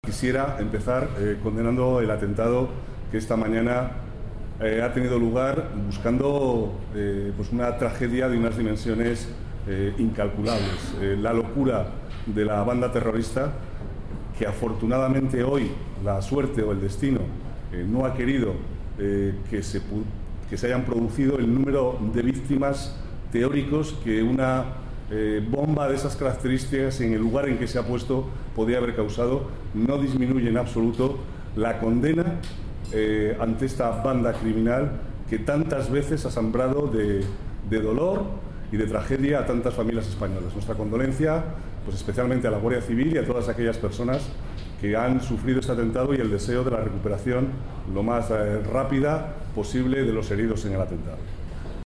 Nueva ventana:Declaraciones vicealcalde, Manuel Cobo: condena atentado terrorista Burgos